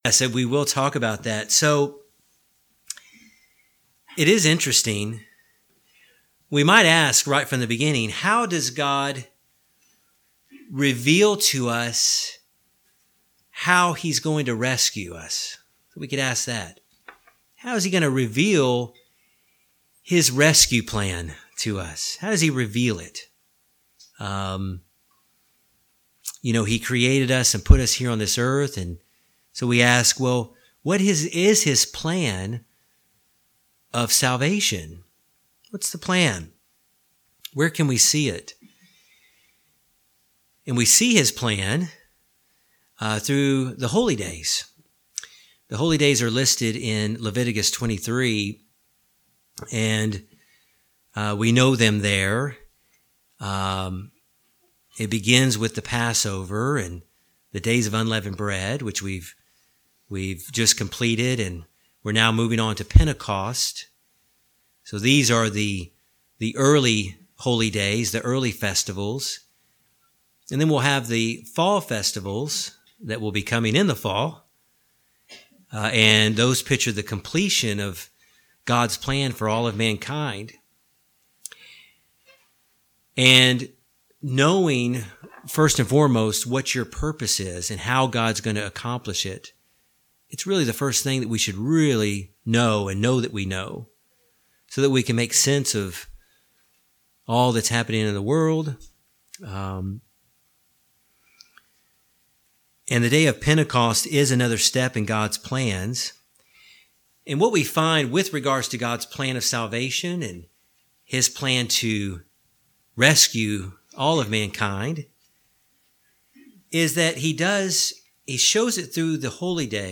Bible Study: Pentecost has many names but only one significance which is God's Holy Spirit being freely given to mankind to better enhance his relationship to Him.